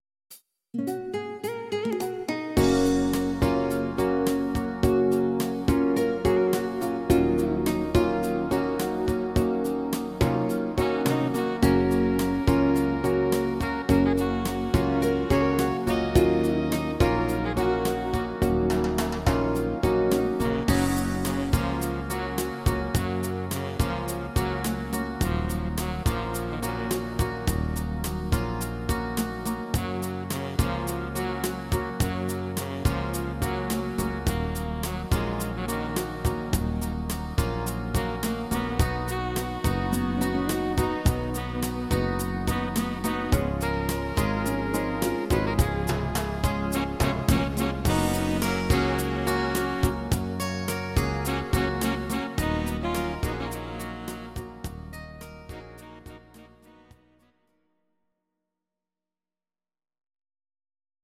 Audio Recordings based on Midi-files
German, 2000s